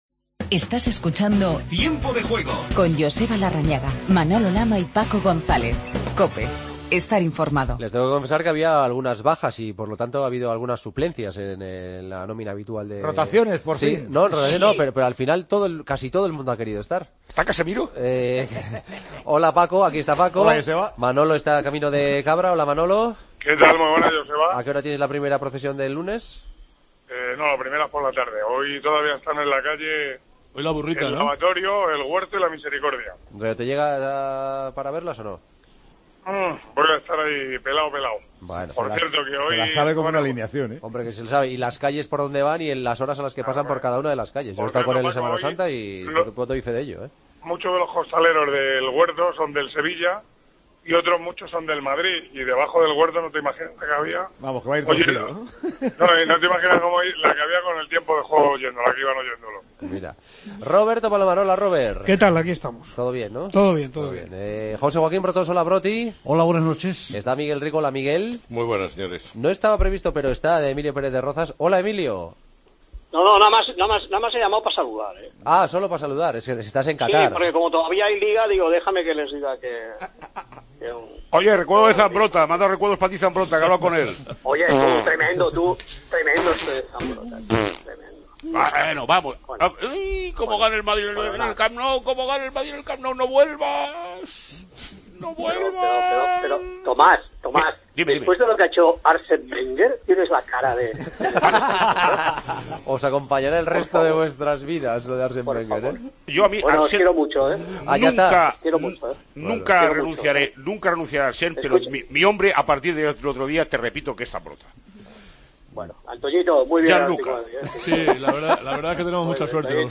Entrevista a Marcelino, entrenador del Villarreal.